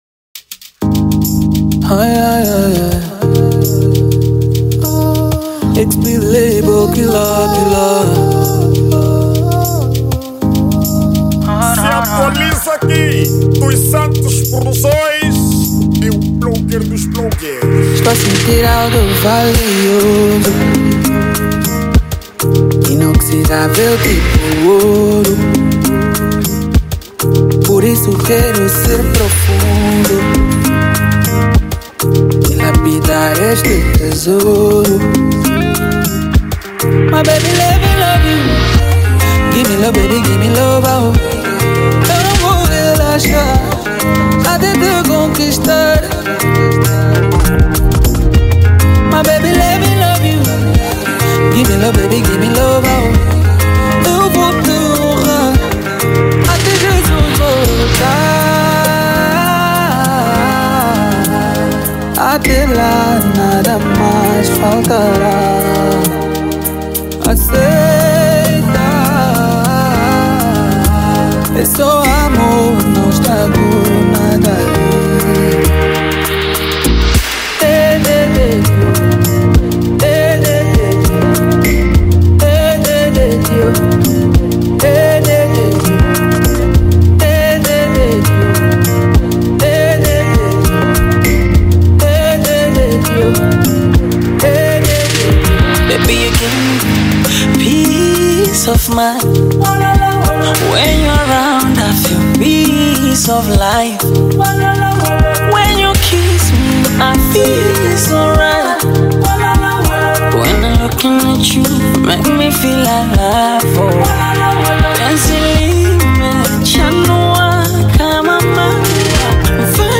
ESTILO DA MÚSICA:  Afro Pop
MUSIC STYLE: Afro Pop